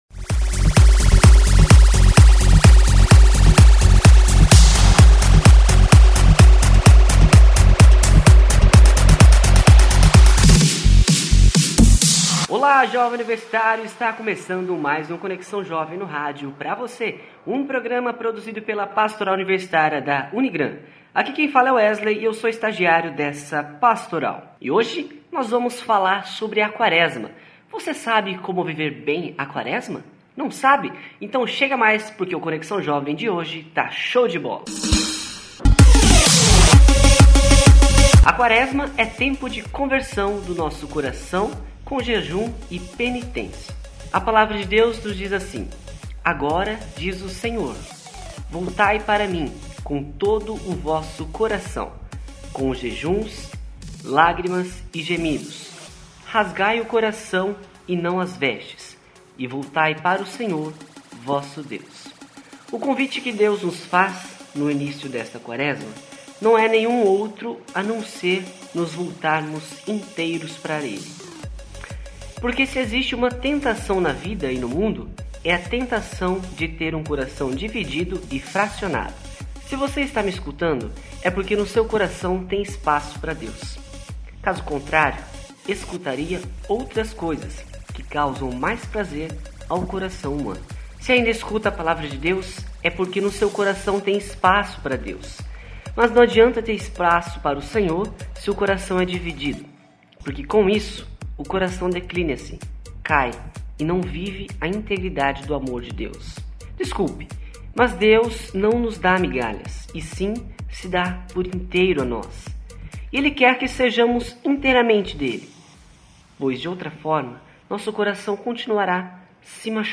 Bate Papo